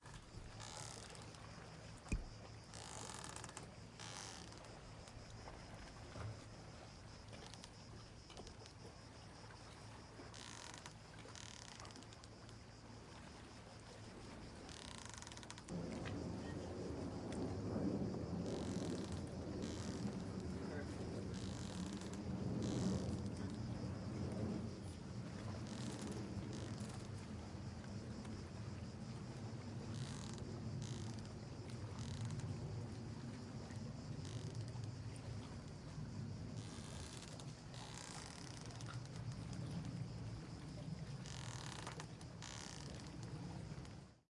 Montenegro » Ambience Sea Boat Night Ropes 3
描述：rope squeaks on boat
标签： Night Sea Boat Ropes Ambience
声道立体声